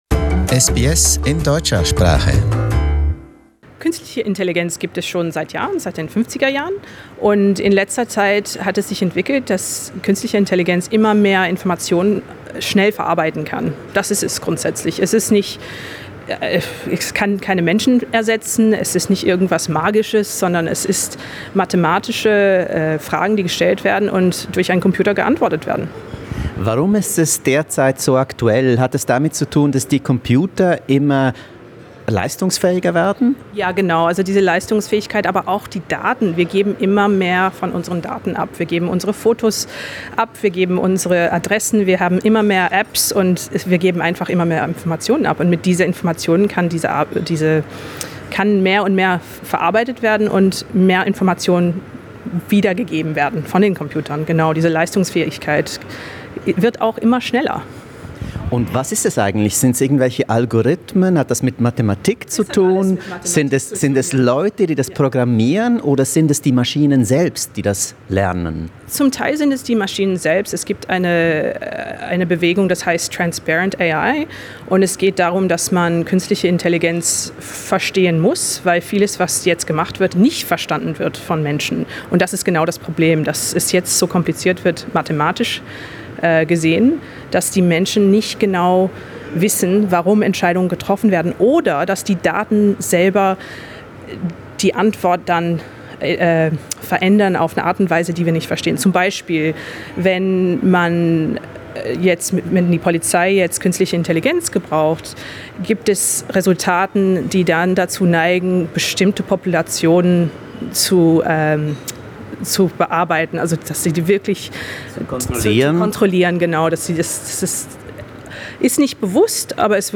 The interview offers some fascinating insights into the AI revolution, its technical aspects, scope and human rights considerations.